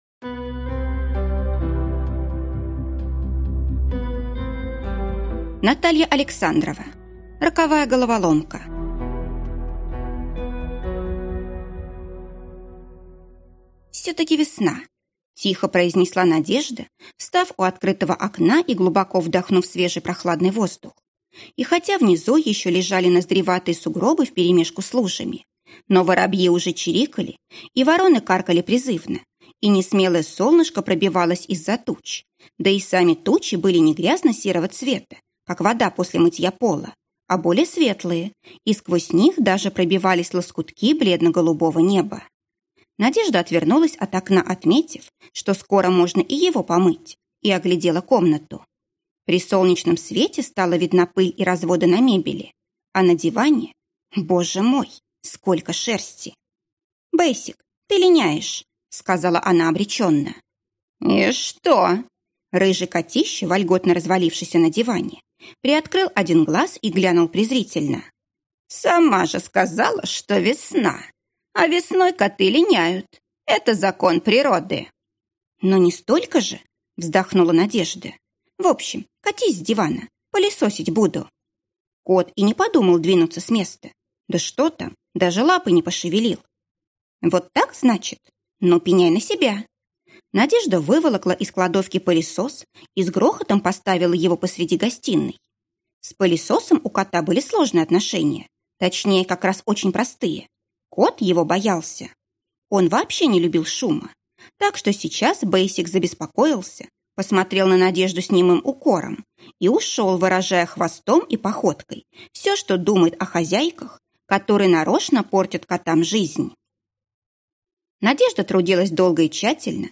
Аудиокнига Роковая головоломка | Библиотека аудиокниг